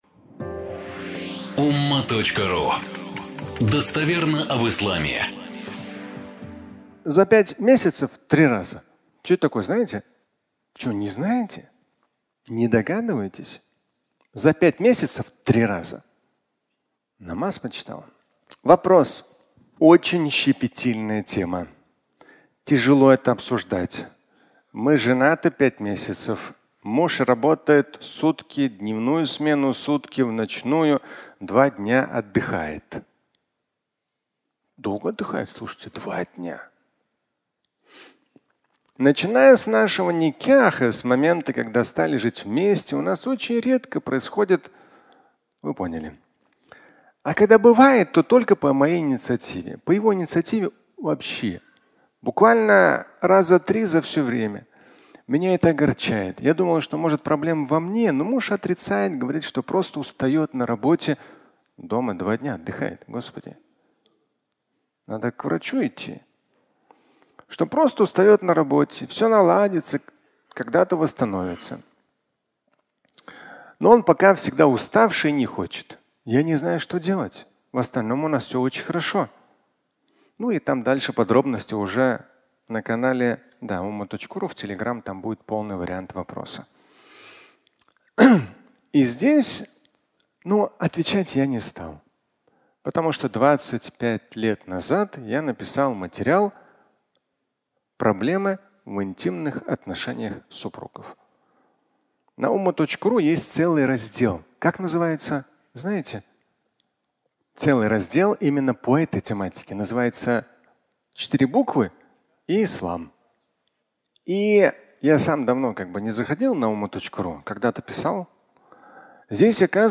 За 5 мес 3 раза (аудиолекция)